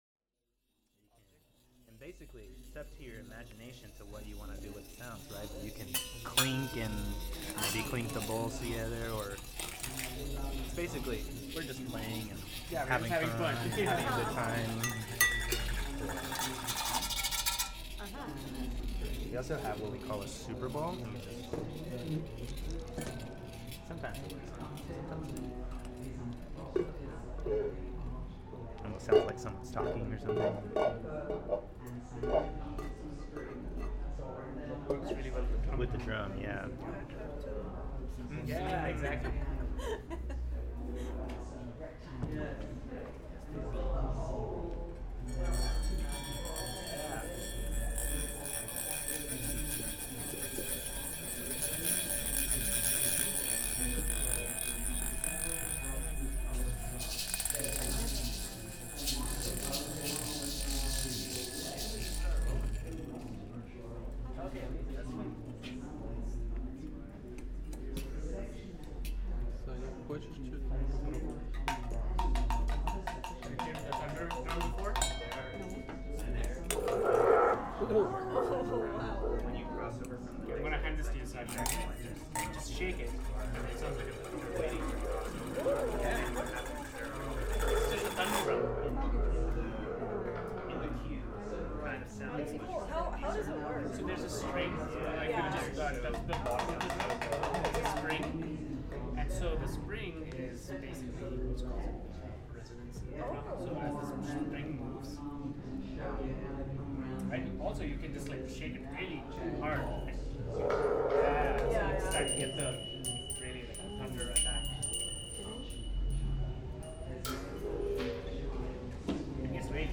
Jack Straw’s recording studio was arranged in a way to evoke the feeling of spending time in one’s home.
The group edited these sounds into a soundscape, which was then integrated into the sounds of the installation back in the New Media Gallery.
Listen: we being so Workshop Soundscape